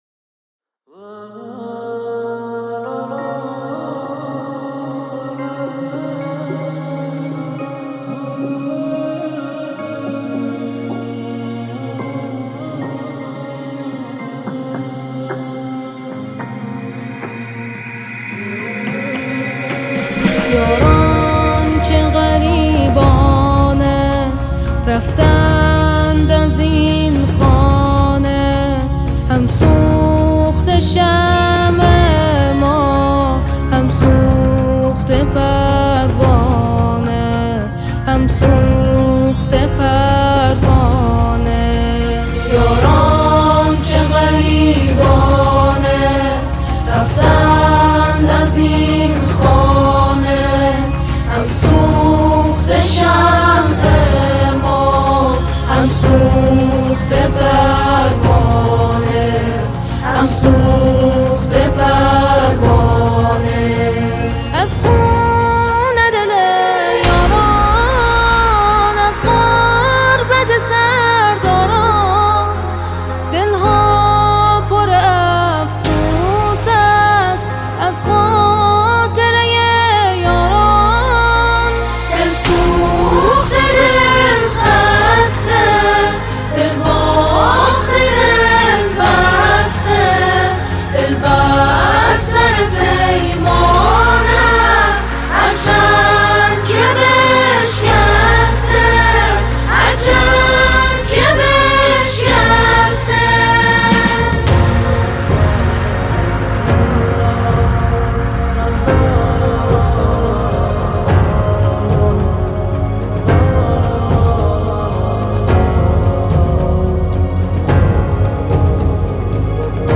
ژانر: سرود